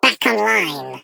Sfx_tool_spypenguin_vo_rebuilt_05.ogg